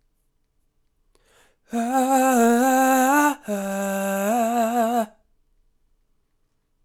Index of /99Sounds Music Loops/Vocals/Melodies